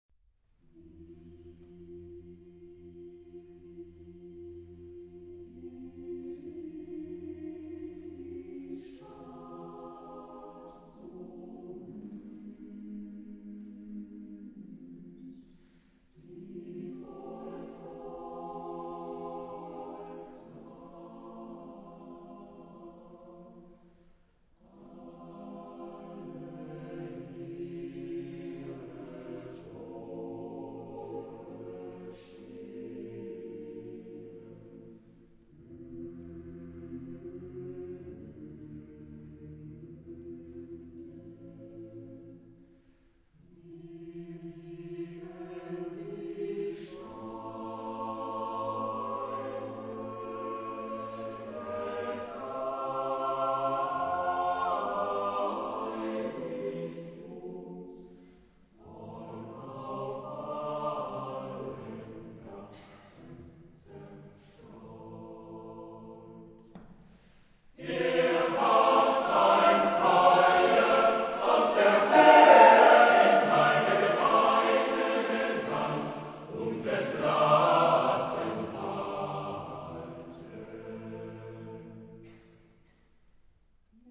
Trauermotette ...
Genre-Style-Form: Sacred ; Motet
Type of Choir: SSAATTBB  (8 mixed voices )
Tonality: F minor